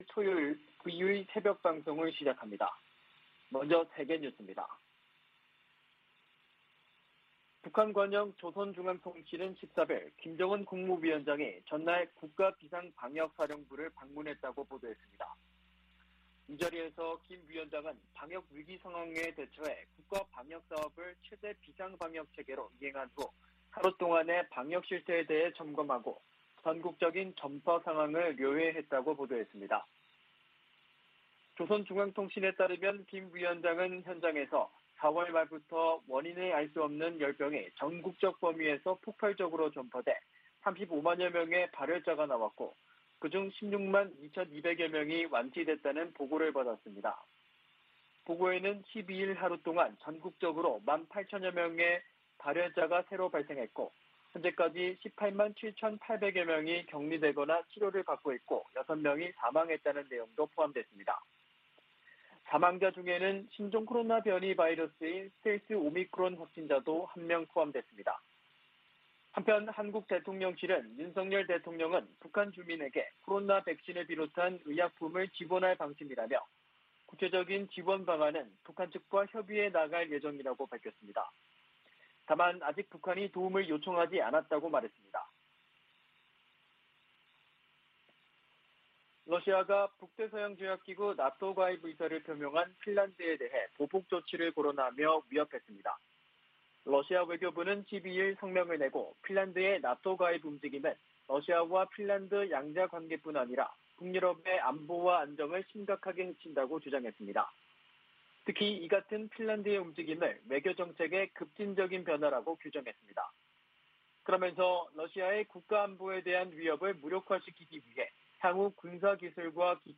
VOA 한국어 '출발 뉴스 쇼', 2022년 5월 14일 방송입니다. 백악관은 북한이 이달 중 핵실험 준비를 끝낼 것으로 분석했습니다.